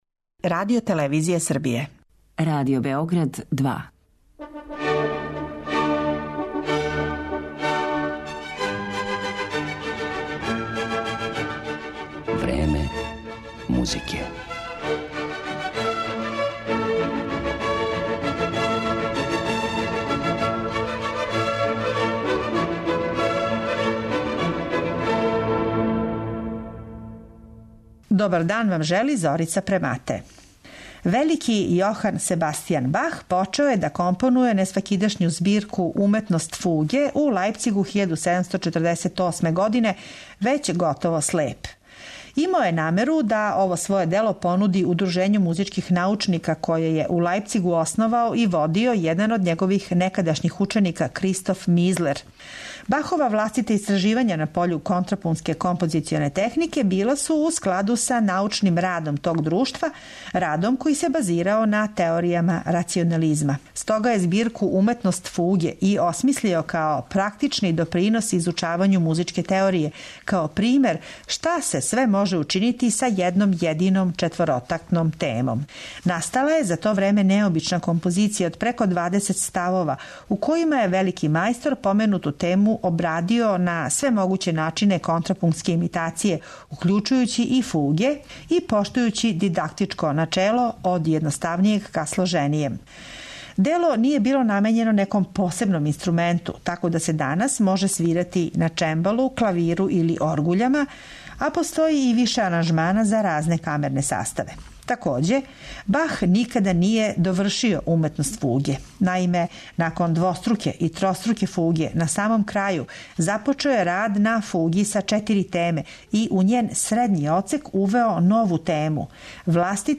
углавном оргуљске композиције са темом Бе-А-Це-Ха